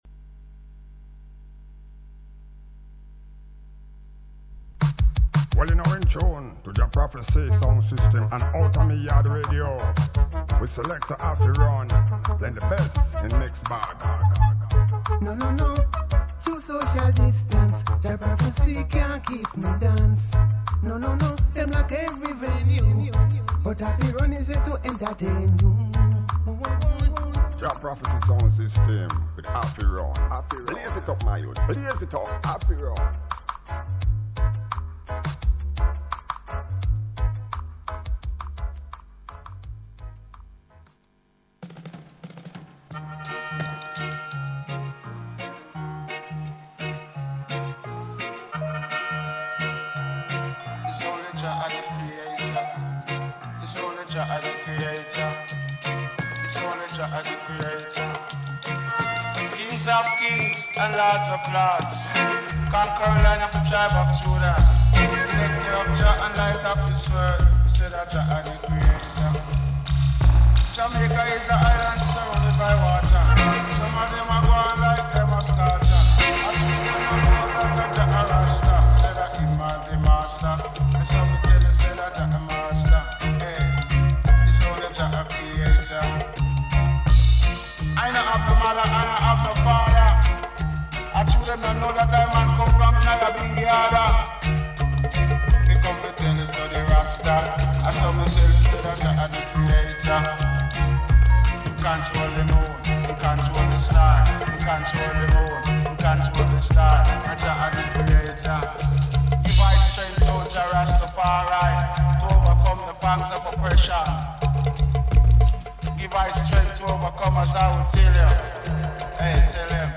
LIVE AND DIRECT